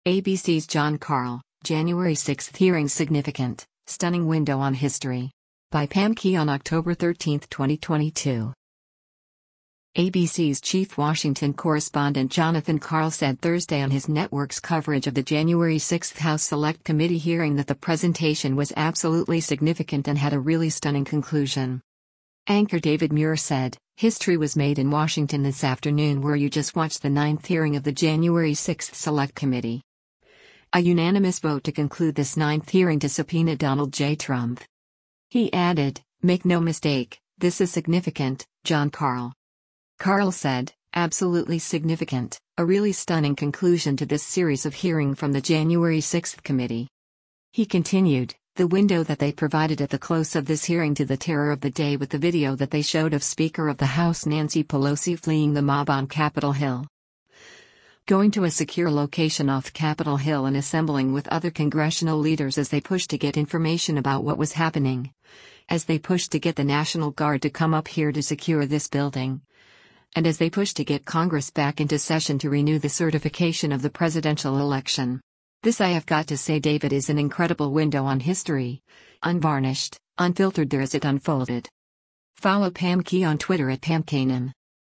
ABC’s chief Washington correspondent Jonathan Karl said Thursday on his network’s coverage of the January 6 House Select Committee hearing that the presentation was “absolutely significant” and had “a really stunning conclusion.”